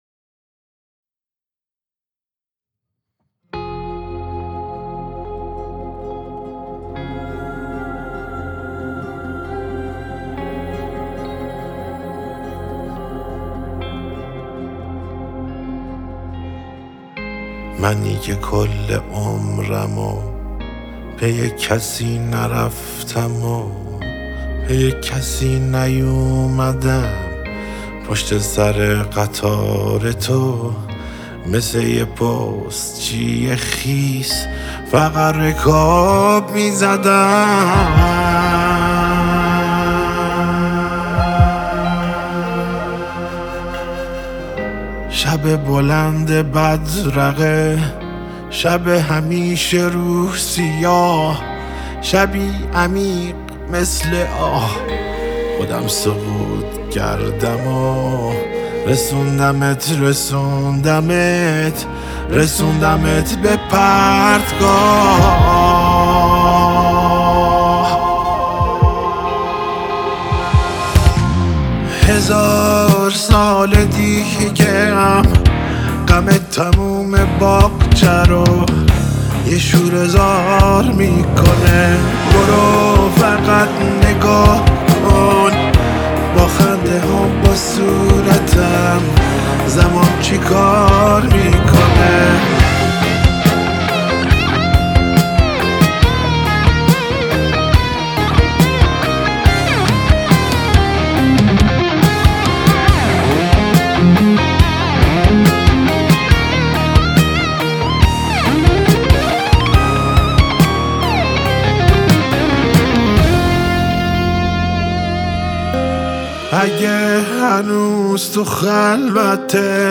آهنگ احساسی و متفاوت